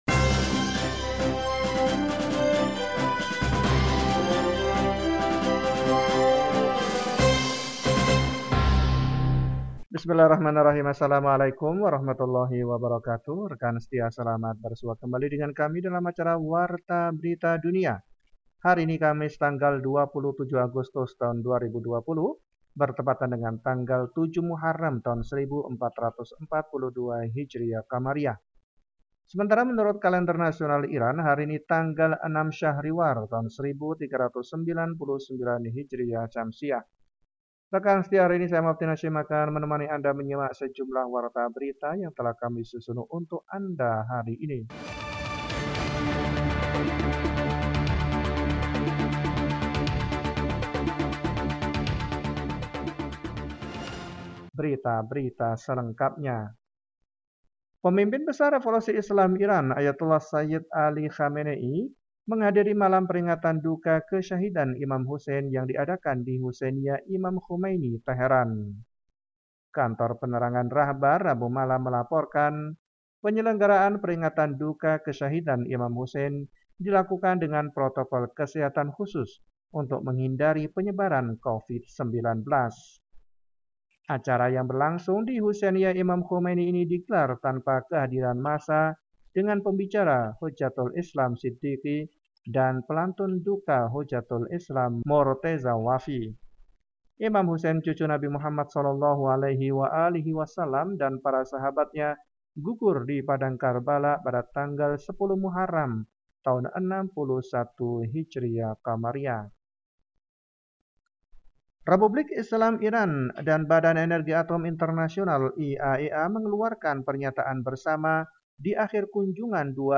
Warta Berita 27 Agustus 2020